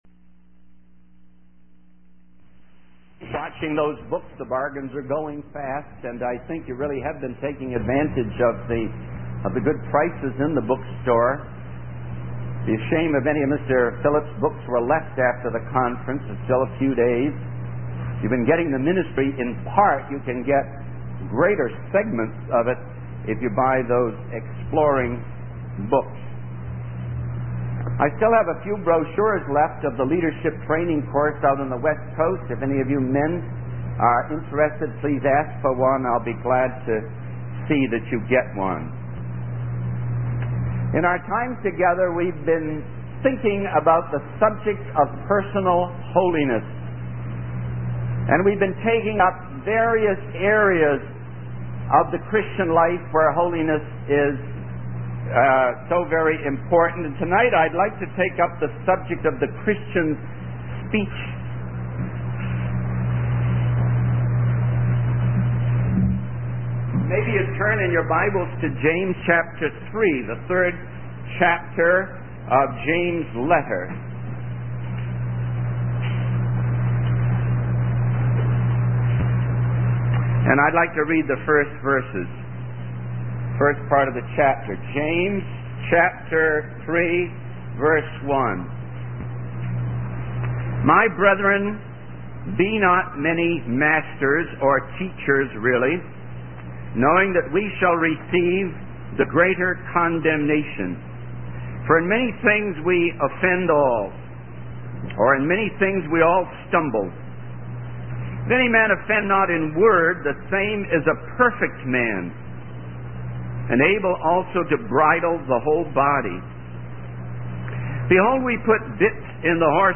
In this sermon, the speaker emphasizes the importance of keeping our speech brief and watching our words. He shares a story about a lady whose dog died and the humorous chain of events that followed.